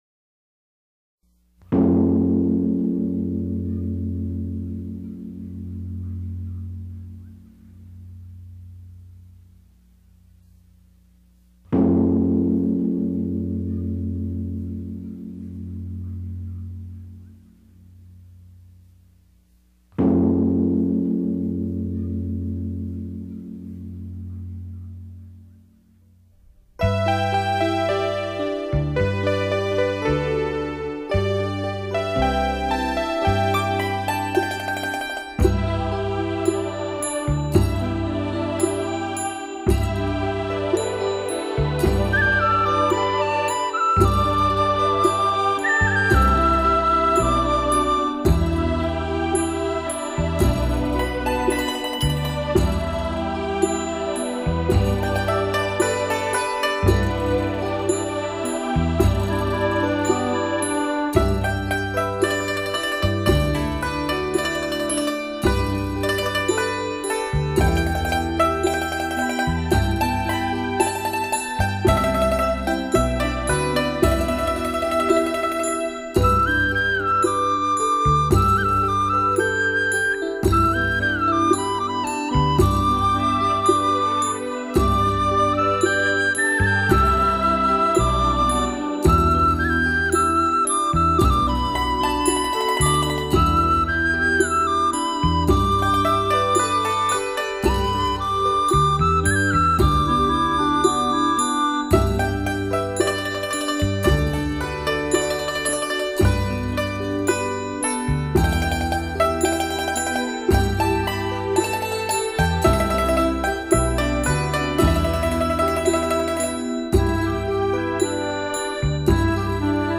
此曲於一般大型法會中放焰火時用。